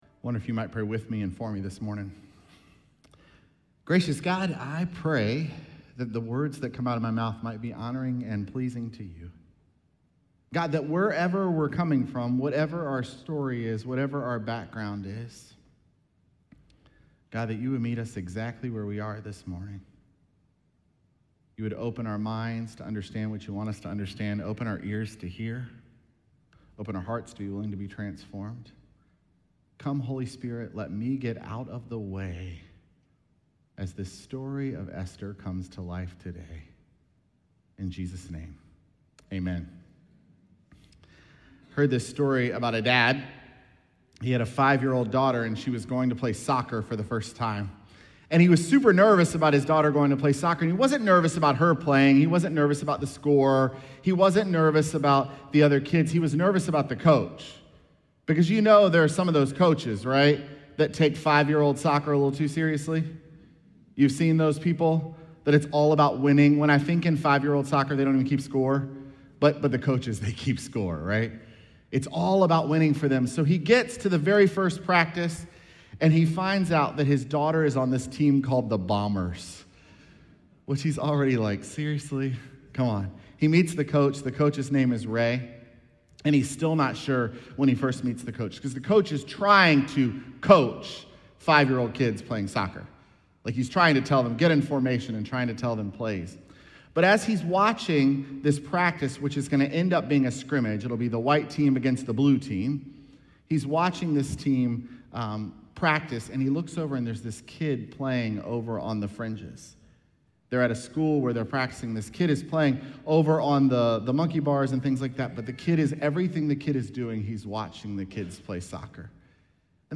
Sermons
Jul7SermonPodcast.mp3